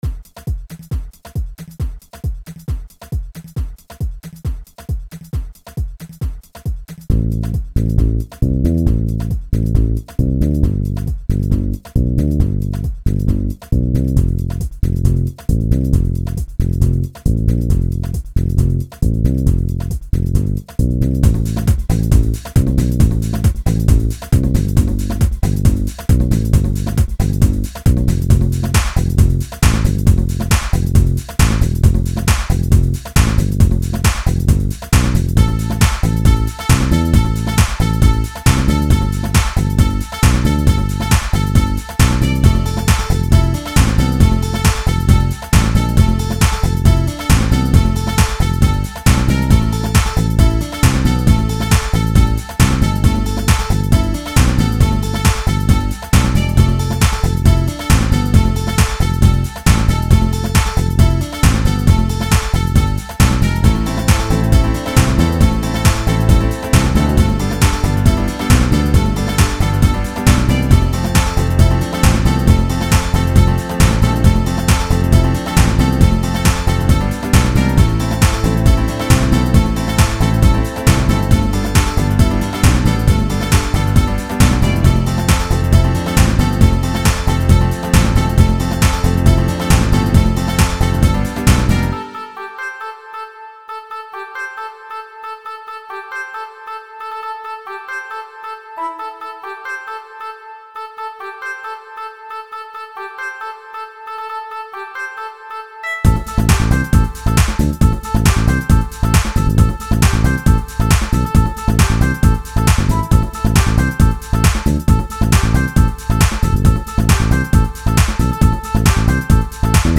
Музыкальный хостинг: /Танцевальная